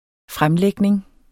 Udtale [ ˈfʁamˌlεgneŋ ]